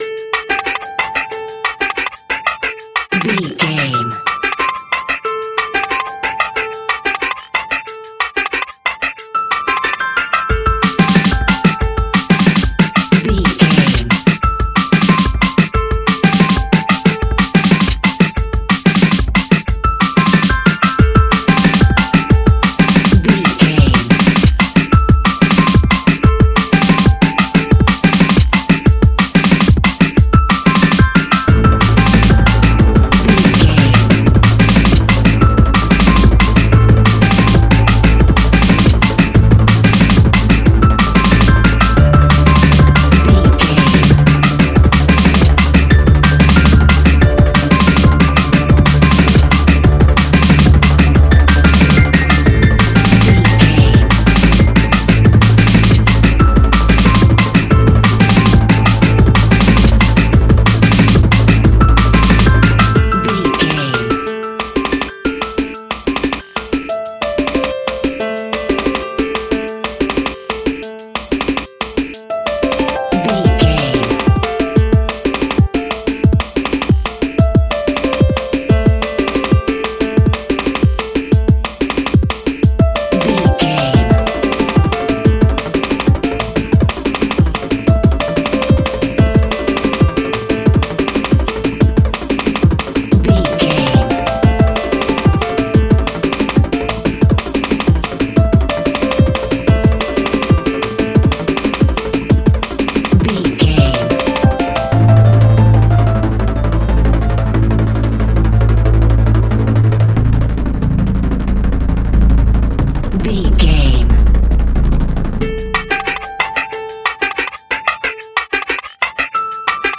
Bouncing Drum and Bass.
Aeolian/Minor
Fast
futuristic
hypnotic
industrial
frantic
aggressive
dark
drum machine
piano
break beat
sub bass
Neurofunk
synth leads